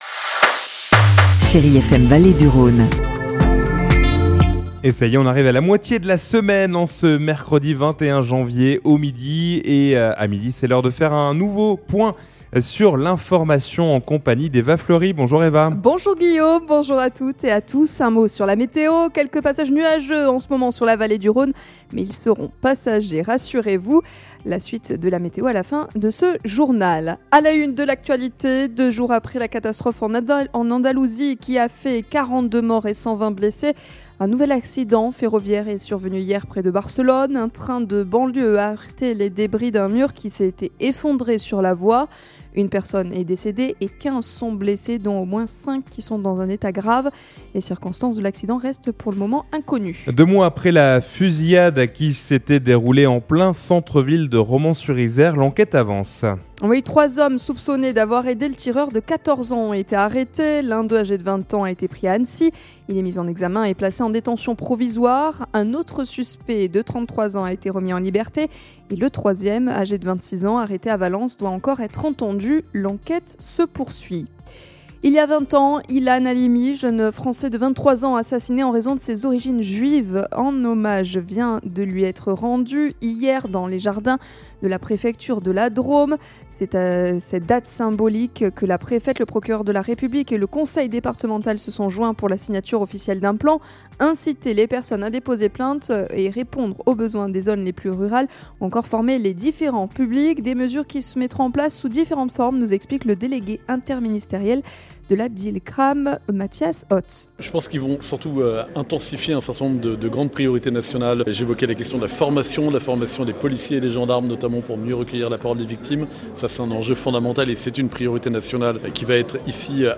Mercredi 21 janvier : Le journal de 12h